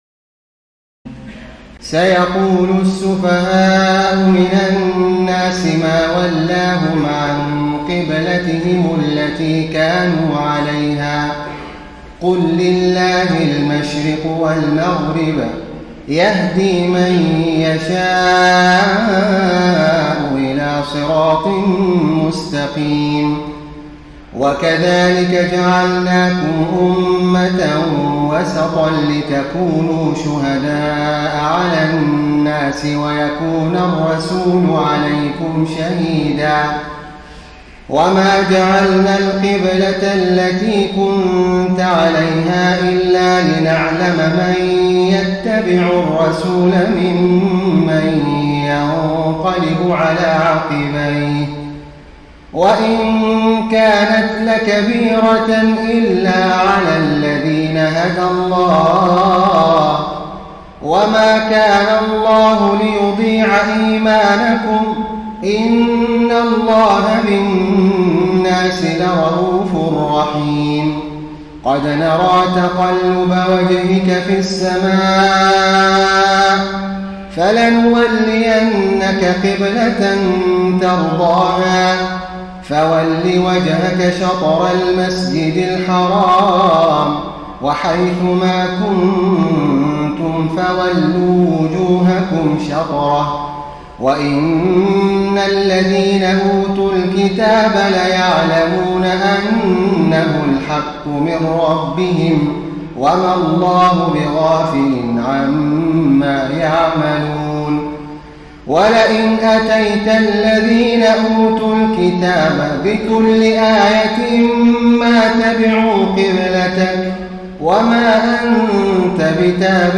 تراويح الليلة الثانية رمضان 1433هـ من سورة البقرة (142-203) Taraweeh 2 st night Ramadan 1433H from Surah Al-Baqara > تراويح الحرم النبوي عام 1433 🕌 > التراويح - تلاوات الحرمين